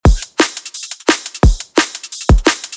drum & bass samples
174BPM Drum Loop 28 Full
174BPM-Drum-Loop-28-Full.mp3